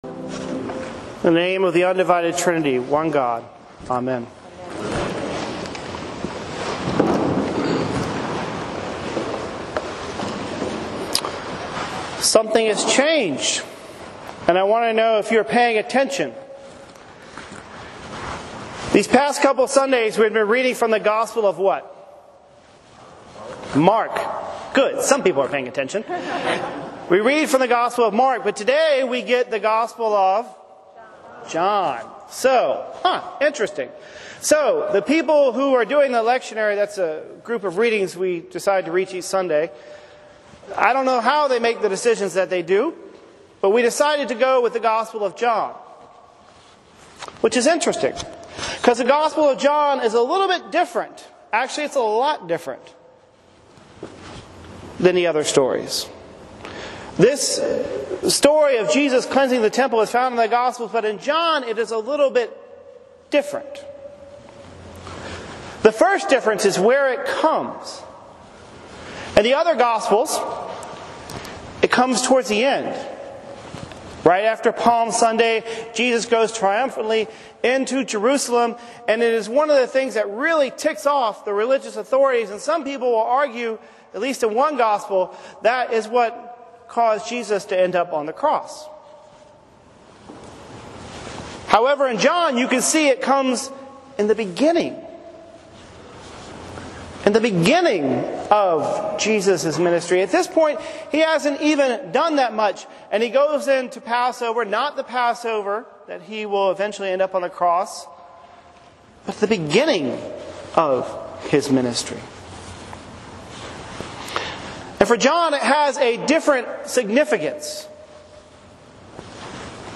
Recent sermons from St. Thomas Parish - Dupont Circle